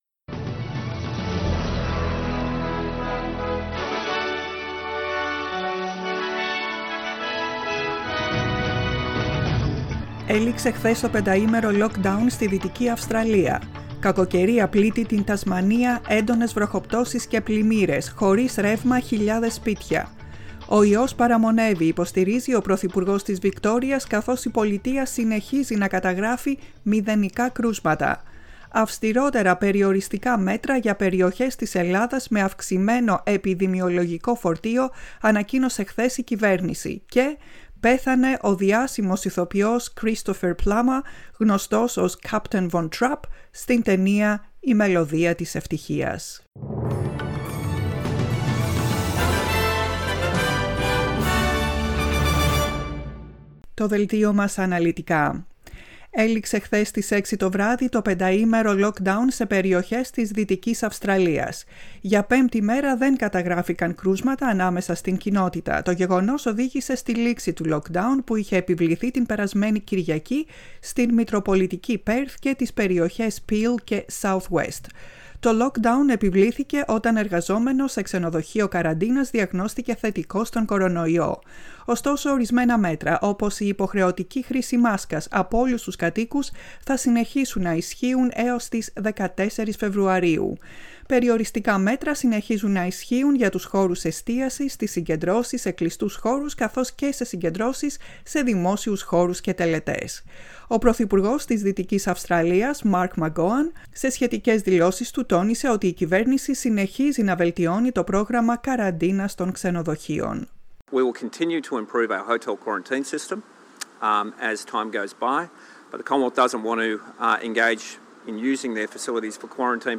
Ειδήσεις στα Ελληνικά, 6.2.2021